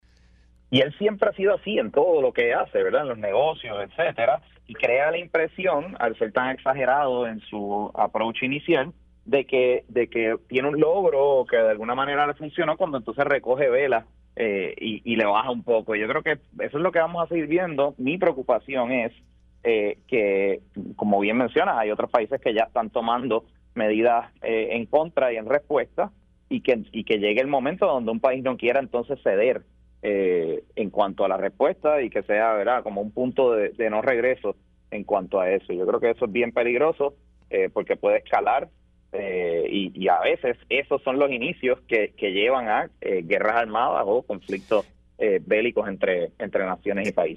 Sus expresiones se dieron en Pega’os en la Mañana, cuando se le preguntó si habrá espacio que Puerto Rico figure como parte de la agenda del Gobierno federal durante este cuatrienio, o si solo será un tiempo perdido.